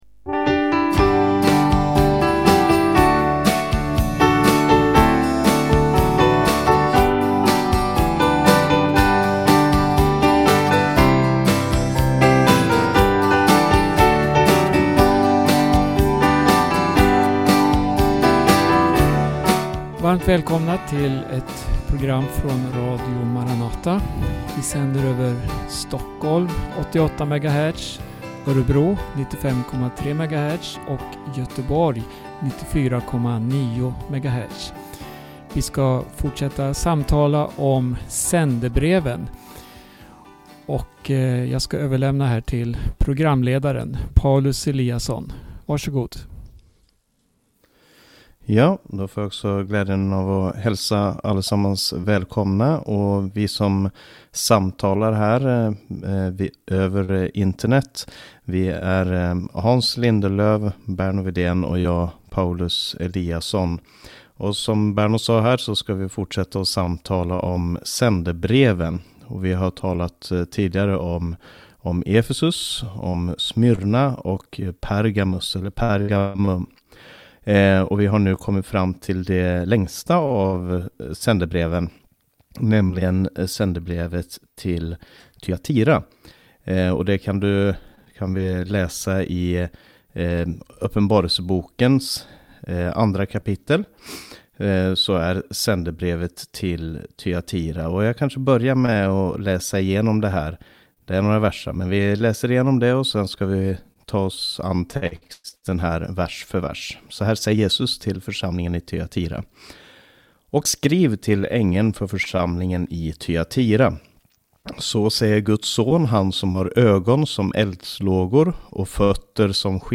samtalar